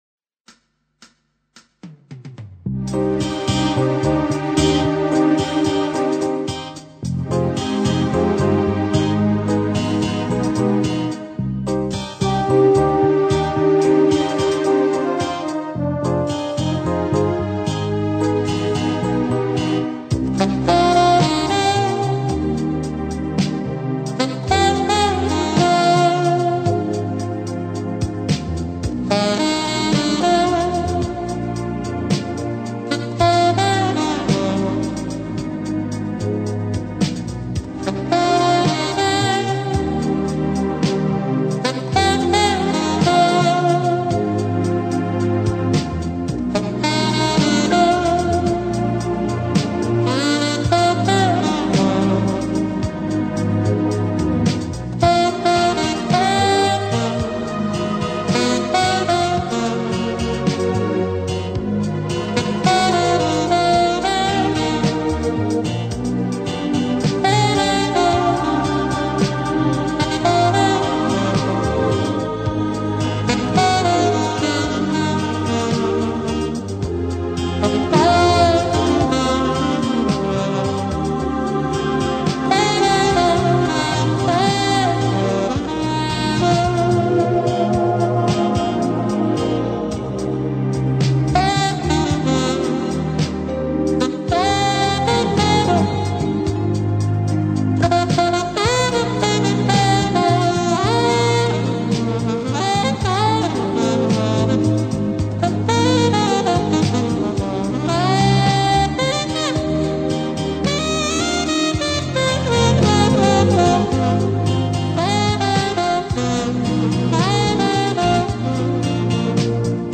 Но лично мне неплохо помогает и классический саксофон.